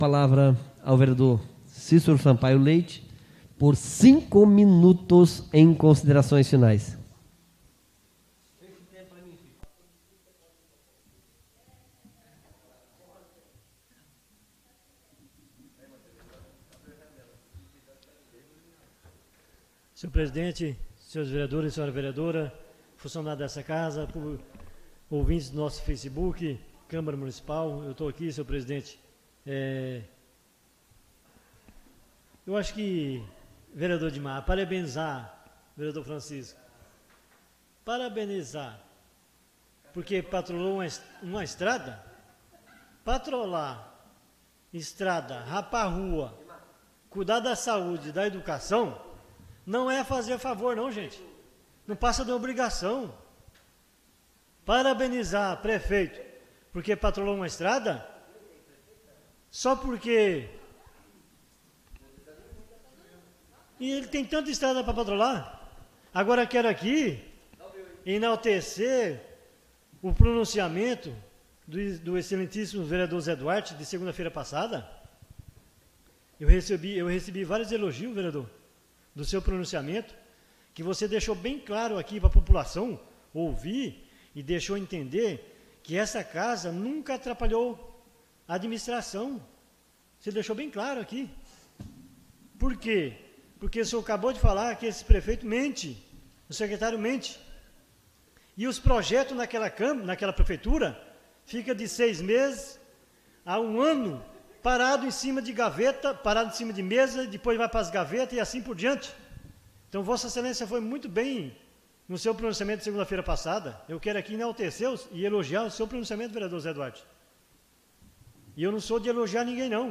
Oradores das Explicações Pessoais (13ª Ordinária da 4ª Sessão Legislativa da 6ª Legislatura)